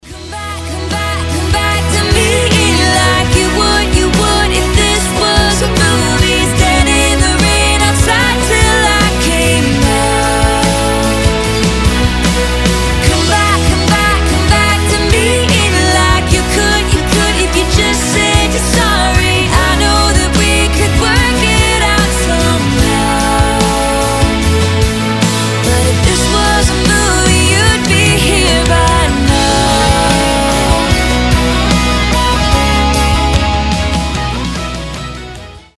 поп , романтические
баллады , кантри , красивый женский голос